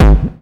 BD DM2-19.wav